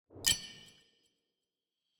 Sword block.wav